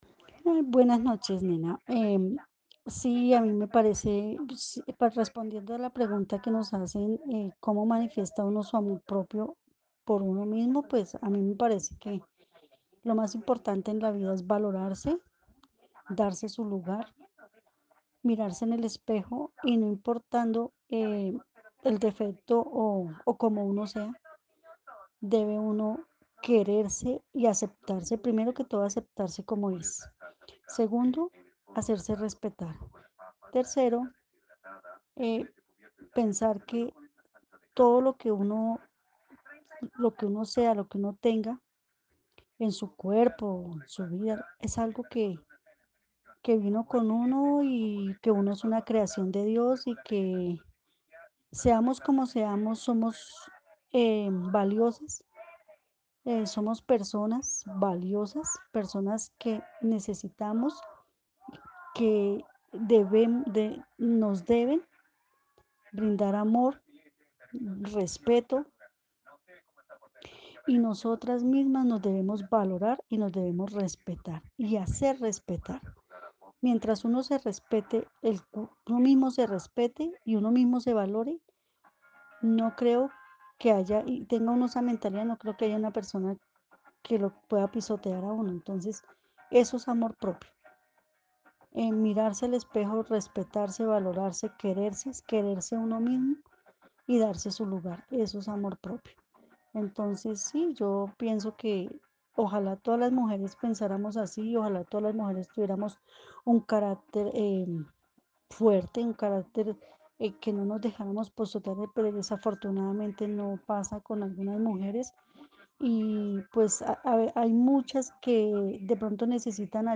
Narración oral de una mujer de la ciudad de Bogotá que desde sus vivencias entiende el amor propio como valorarse y darse su lugar, poder mirarse al espejo, aceptarse y quererse para que nadie pasa por encima de ella. En su relato, resalta la importancia de que muchas mujeres tengan amor propio para atravesar por malos momentos. El testimonio fue recolectado en el marco del laboratorio de co-creación "Postales sonoras: mujeres escuchando mujeres" de la línea Cultura Digital e Innovación de la Red Distrital de Bibliotecas Públicas de Bogotá - BibloRed.